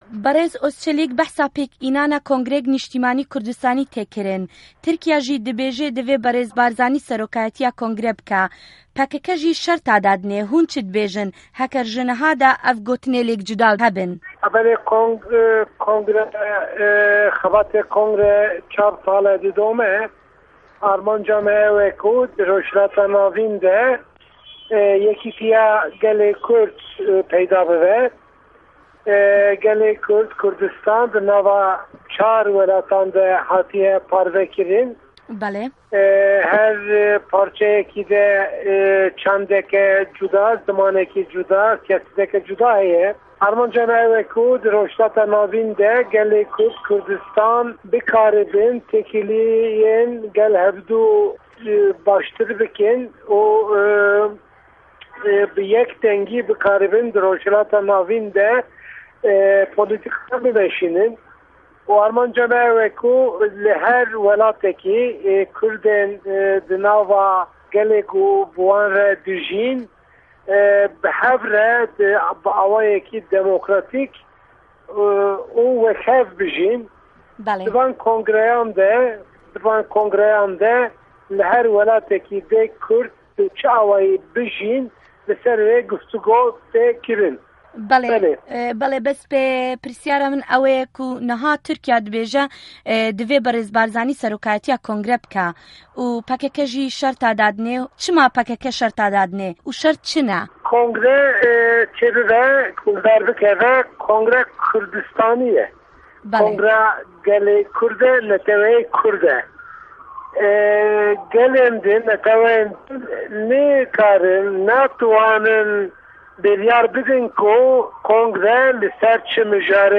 وتووێژ له‌گه‌ڵ ئوسمان ئوزچه‌لیک